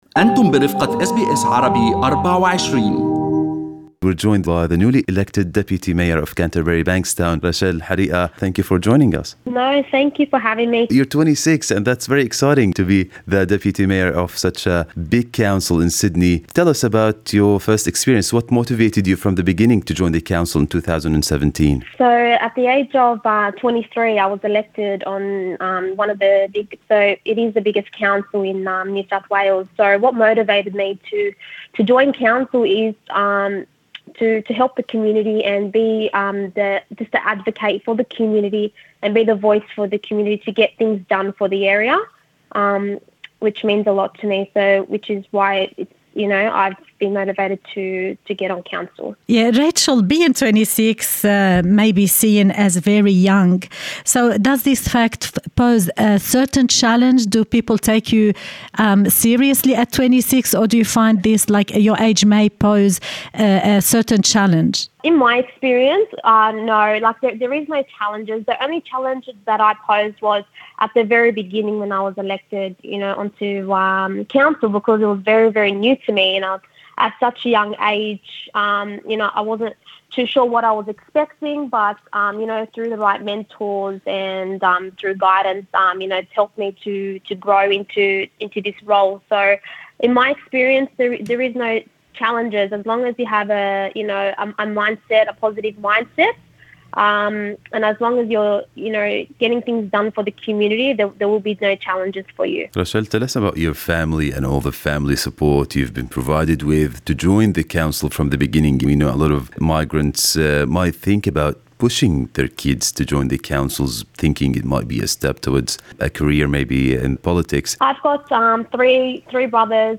وفي حديث لها مع أس بي أس عربي24، قالت راشيل ان الدافع وراء دخولها مجلس البلدية منذ البداية كان مساعدة أفراد المجتمع ومحاولة تحقيق مطالب السكان.